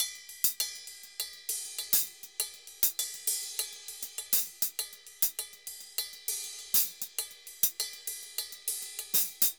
Ride_Samba 100_1.wav